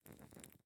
Minecraft Version Minecraft Version snapshot Latest Release | Latest Snapshot snapshot / assets / minecraft / sounds / block / candle / ambient9.ogg Compare With Compare With Latest Release | Latest Snapshot